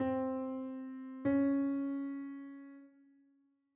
Minor 2nd
C-Minor-Second-Interval-S1.wav